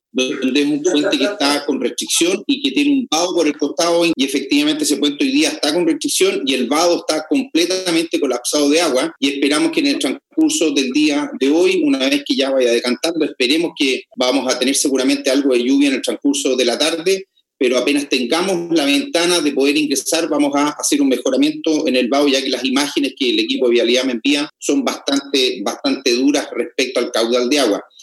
El seremi de Obras Públicas, James Fry, durante la conferencia de prensa realizada tras el Comité Operativo de Emergencias, analizó la situación que afecta a la región.